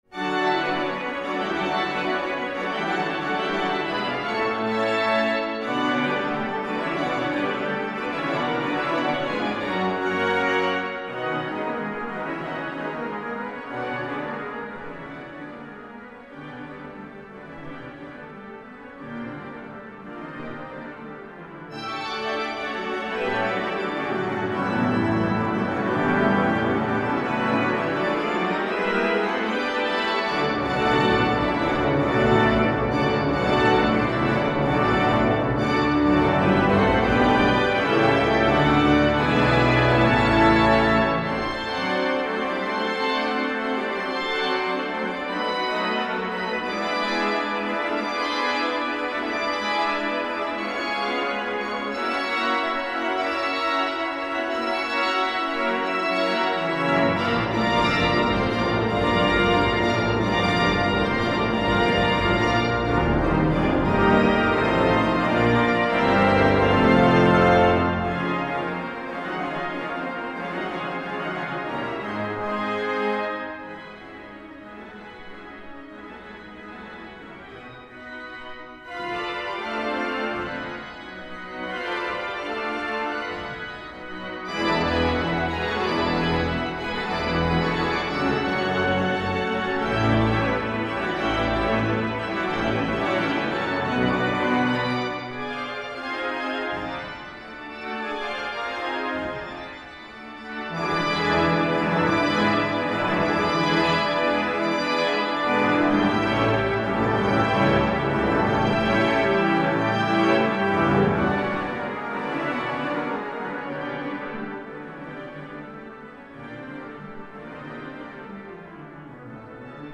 Wäschenbeuren, HEISS-Orgel
Katholische Pfarrkirche St. Johannes Evangelist, 73115 Wäschenbeuren
audio-waeschenbeuren-widor-finale.mp3